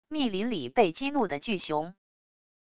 密林里被激怒的巨熊wav音频